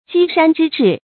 箕山之志 jī shān zhī zhì
箕山之志发音
成语注音ㄐㄧ ㄕㄢ ㄓㄧ ㄓㄧˋ